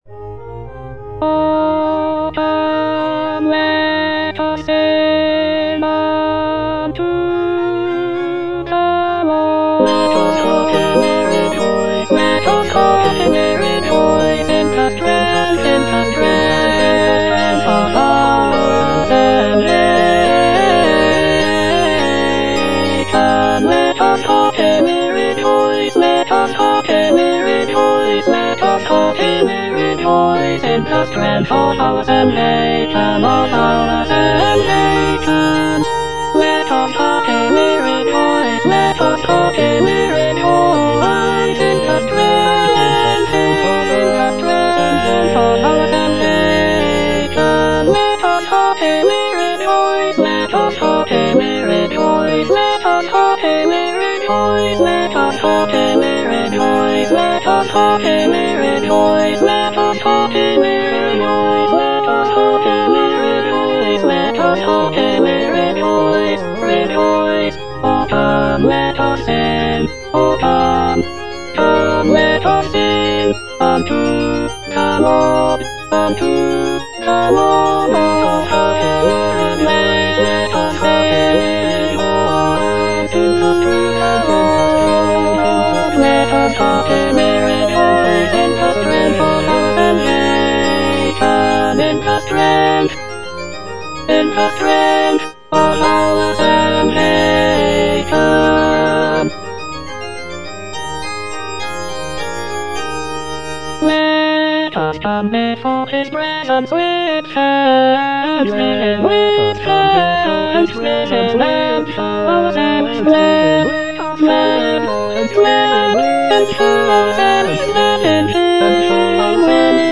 G.F. HÄNDEL - O COME, LET US SING UNTO THE LORD - CHANDOS ANTHEM NO.8 HWV253 (A = 415 Hz) O come, let us sing unto the Lord - Alto (Emphasised voice and other voices) Ads stop: auto-stop
The use of a lower tuning of A=415 Hz gives the music a warmer and more resonant sound compared to the standard tuning of A=440 Hz.